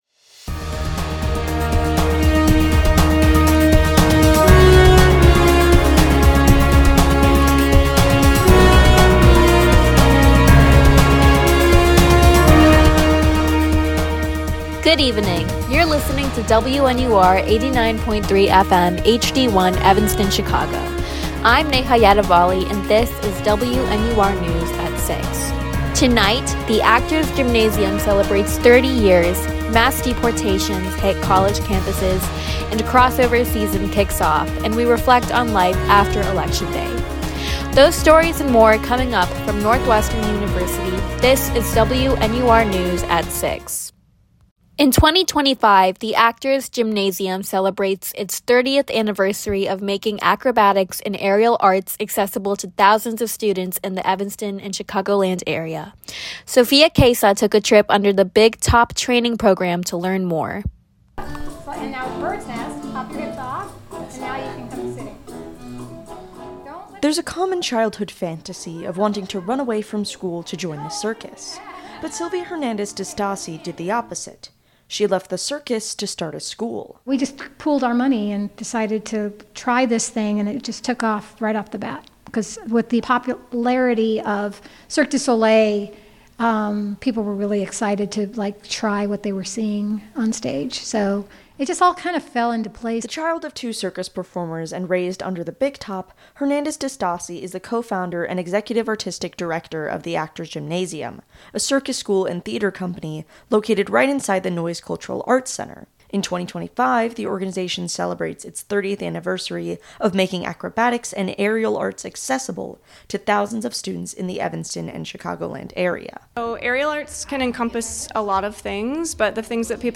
WNUR News broadcasts live at 6pm CST on Mondays, Wednesdays and Fridays on WNUR 89.3 FM.